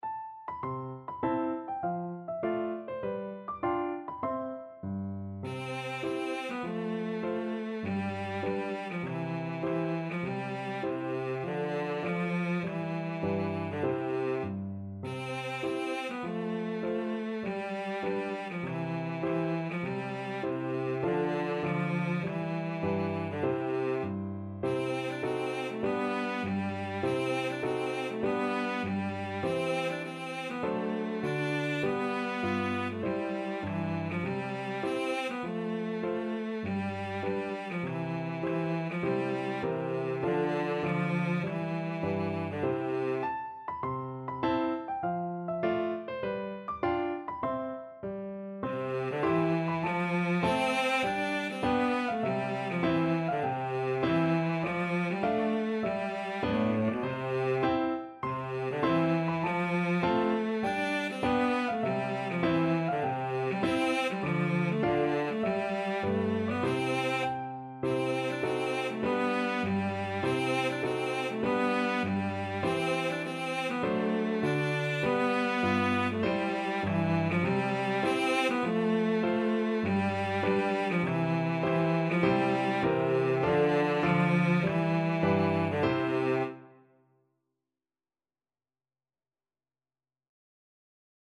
Cello
C major (Sounding Pitch) (View more C major Music for Cello )
4/4 (View more 4/4 Music)
Moderato
Traditional (View more Traditional Cello Music)